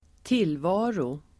Uttal: [²t'il:va:ro]